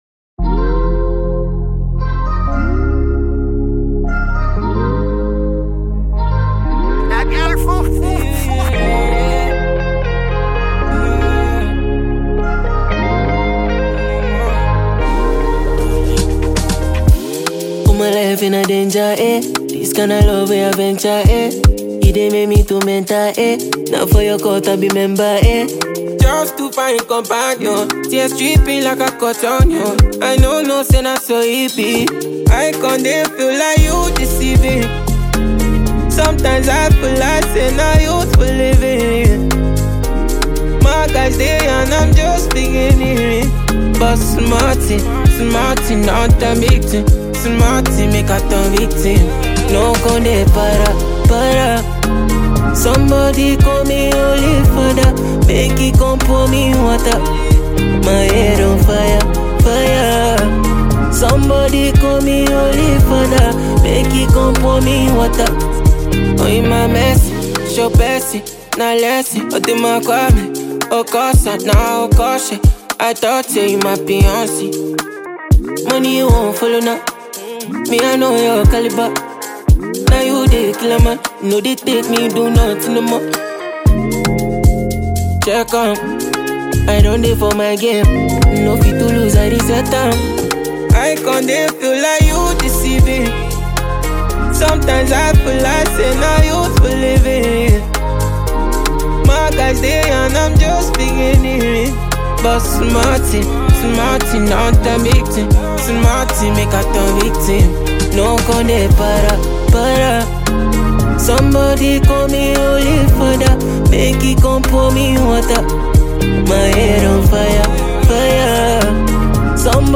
Top-notch Ghanaian musician, performer and singer
afrobeat